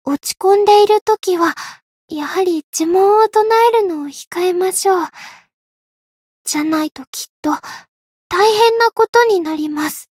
灵魂潮汐-伊汐尔-问候-不开心.ogg